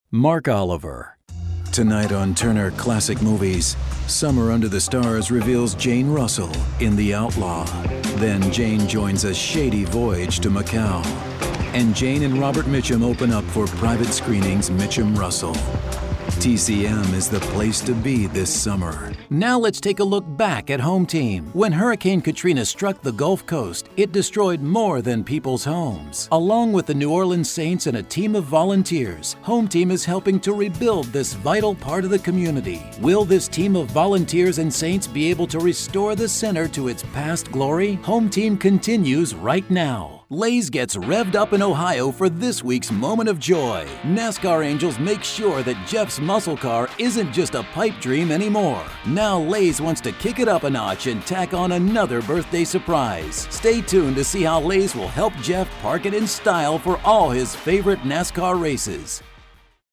Casual, Direct, Friendly
Commercial Voiceover, Promo